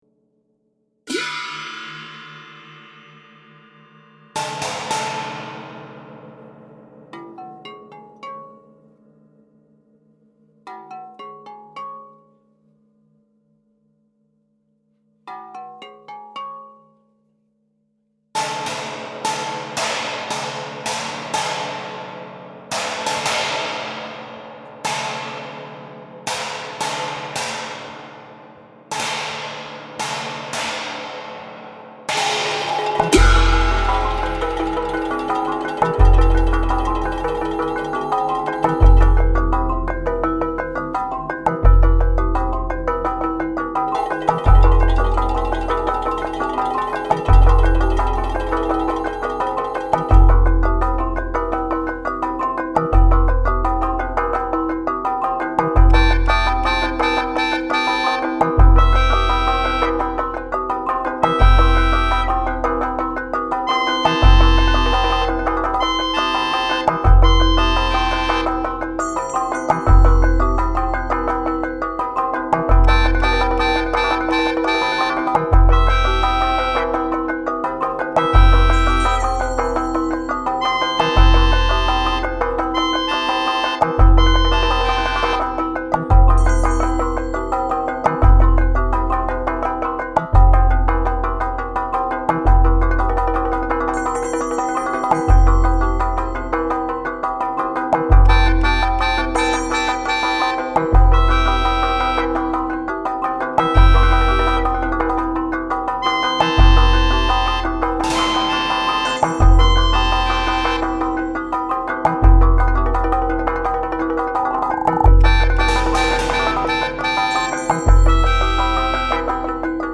Instrumente, Samples